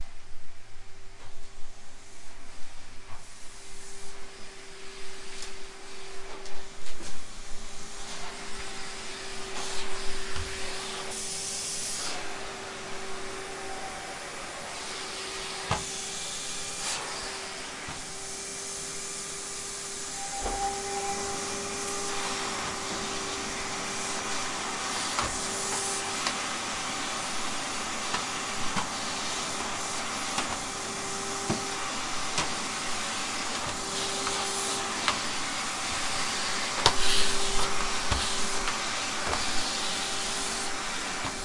描述：吸尘器（亨利）清洁地毯，电机噪音和吸力噪音